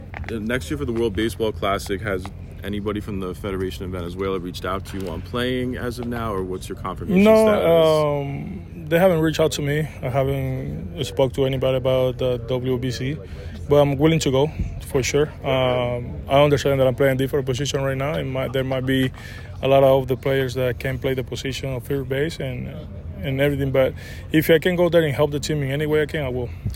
Contreras spoke before the Cardinals’ spring training game against the New York Mets at Roger Dean Chevrolet Stadium.